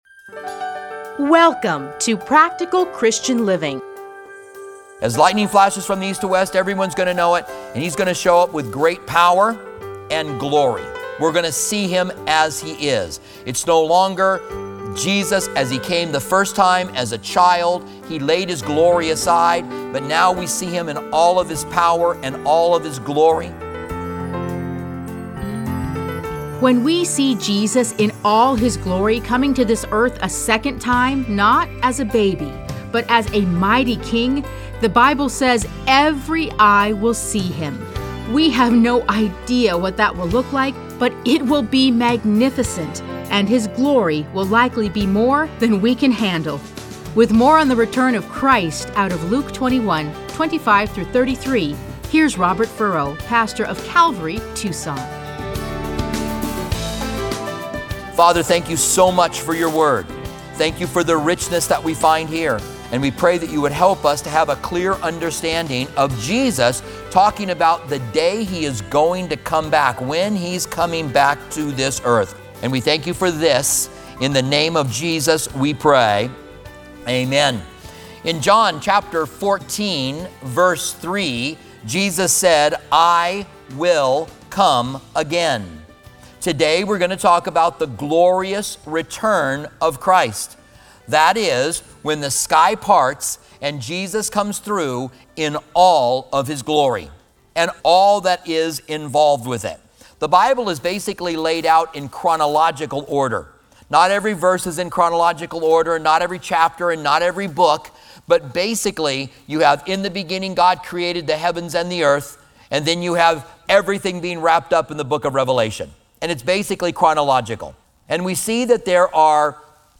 Listen to a teaching from Luke 21:25-33.